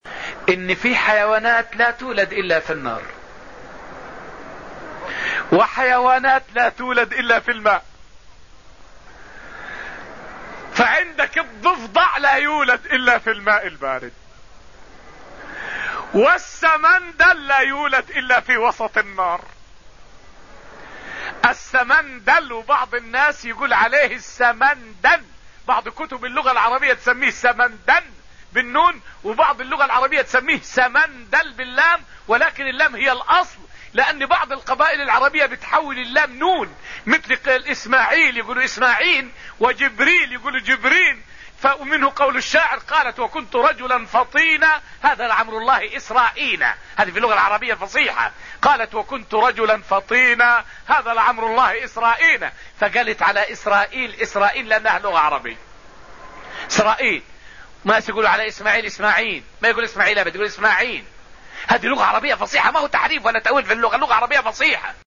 فائدة من الدرس الخامس عشر من دروس تفسير سورة النجم والتي ألقيت في المسجد النبوي الشريف حول اسمي إسماعيل وإسماعين عربيتان فصيحتان.